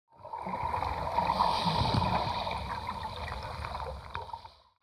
دانلود آهنگ موج 7 از افکت صوتی طبیعت و محیط
دانلود صدای موج 7 از ساعد نیوز با لینک مستقیم و کیفیت بالا
جلوه های صوتی